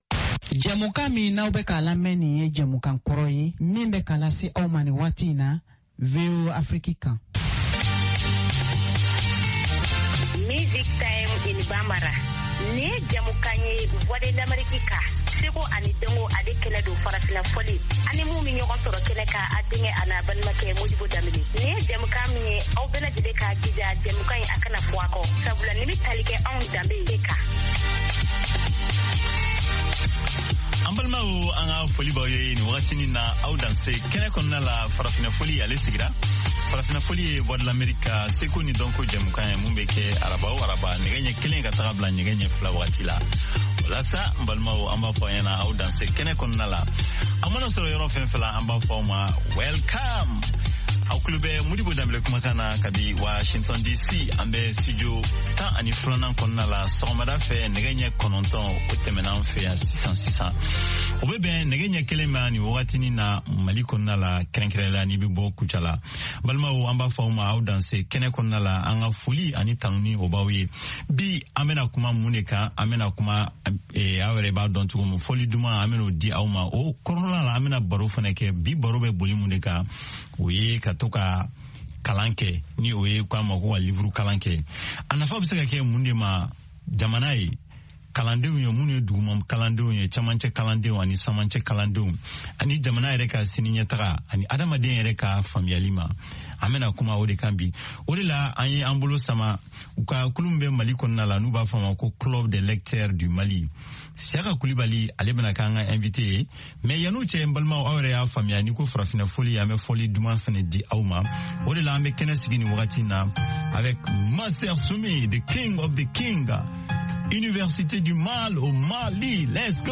Bulletin d’information de 17 heures
Bienvenu dans ce bulletin d’information de VOA Afrique.